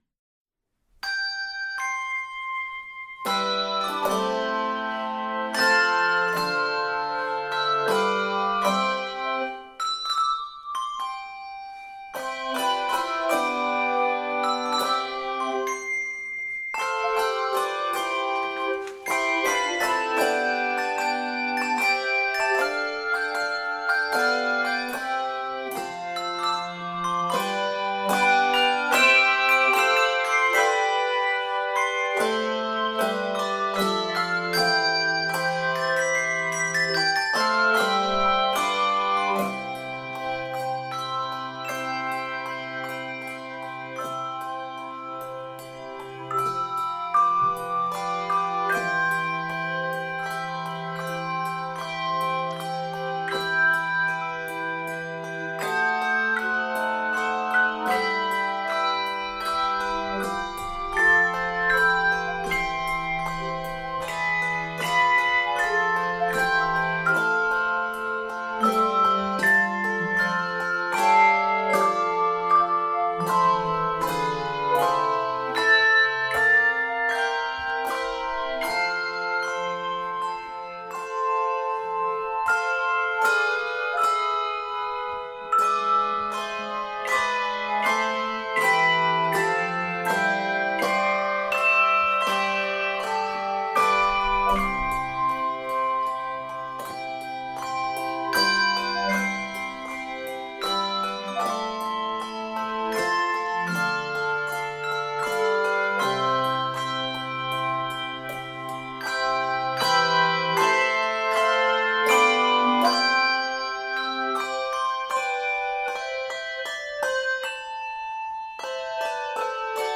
Key of C Major.